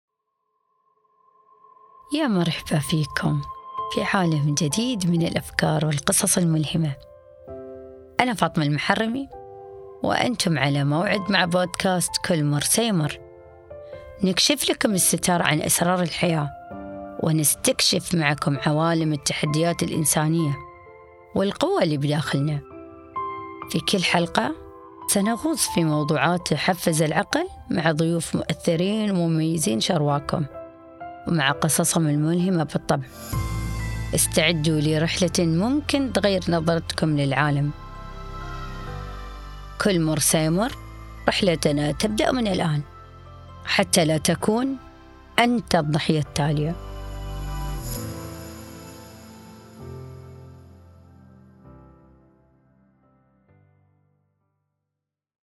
كل مر سيمر - مقطع ترويجي